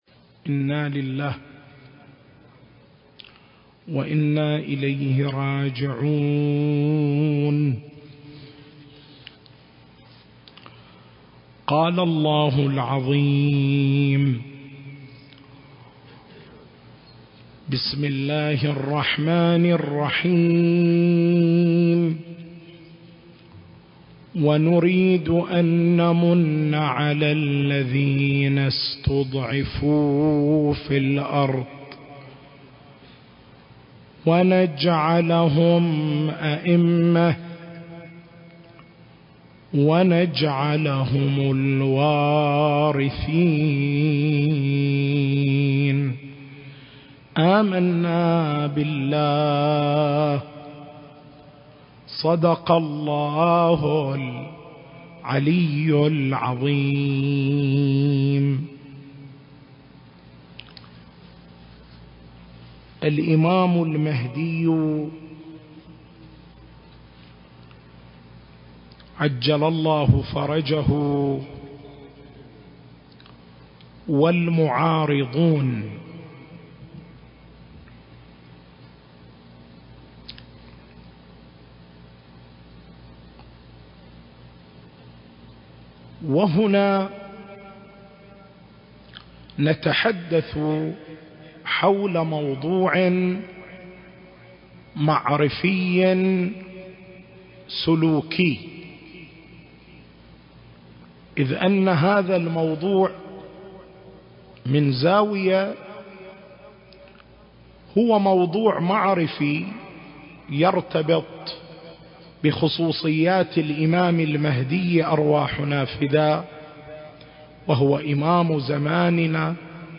المكان: حسينية الملا عبد الله الزين - القطيف التاريخ: 2024